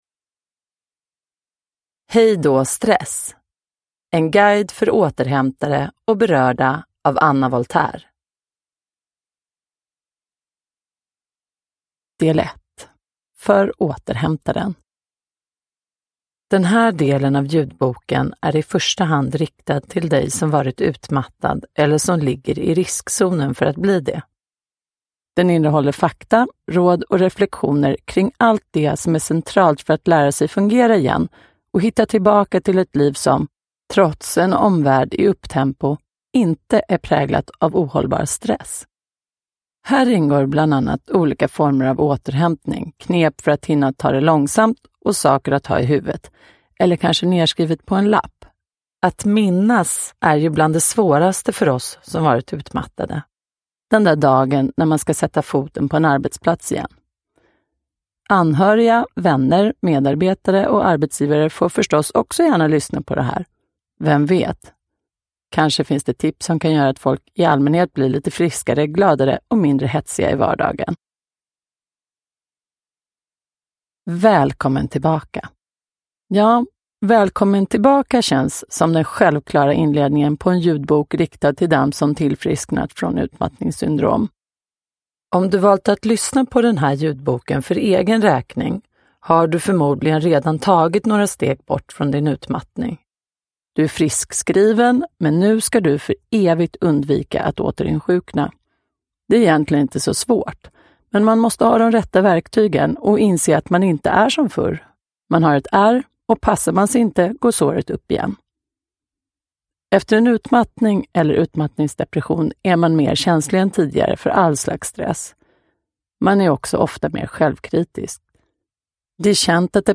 Hejdå stress! : en guide för återhämtare och berörda – Ljudbok – Laddas ner